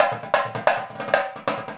Indian Drum 05.wav